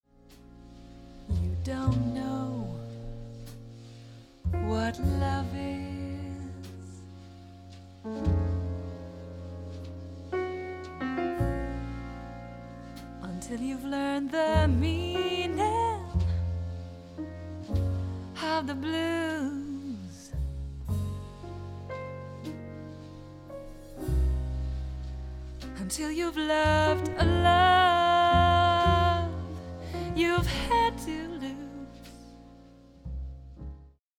jazz standards